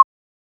edm-perc-07.wav